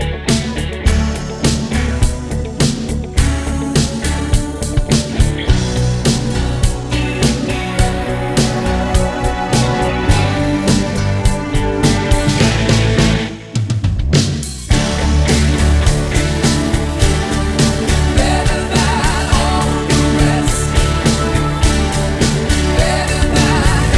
Three Semitones Down Pop (1980s) 4:09 Buy £1.50